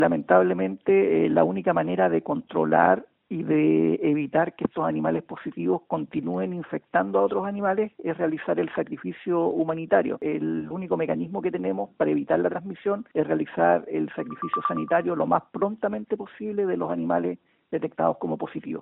En conversación con Radio Bío Bío en Valdivia, el director regional del SAG, Carlos Burgos, confirmó que los primeros casos confirmados de anemia infecciosa equina corresponden a tres caballos que estaban en un corral cercano a la localidad de Malalhue, en la comuna de Lanco.